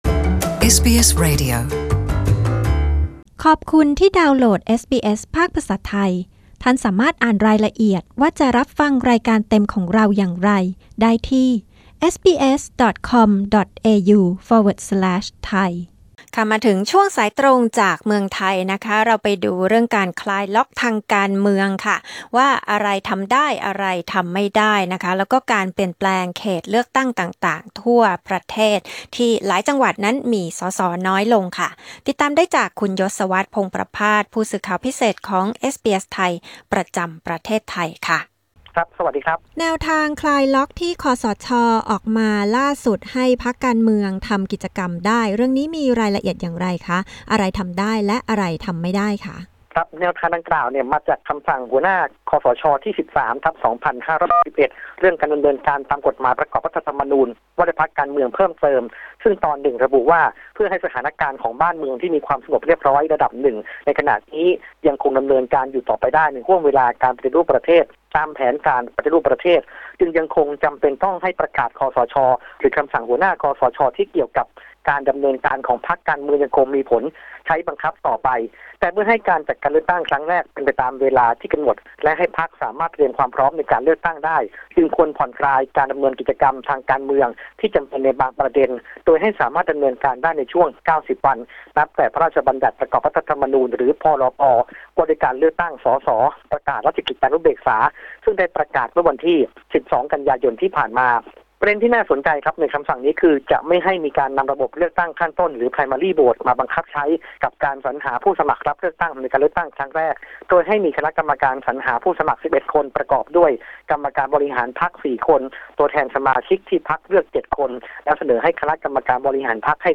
รายงานจากประเทศไทย